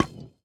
Minecraft Version Minecraft Version latest Latest Release | Latest Snapshot latest / assets / minecraft / sounds / block / decorated_pot / insert_fail3.ogg Compare With Compare With Latest Release | Latest Snapshot
insert_fail3.ogg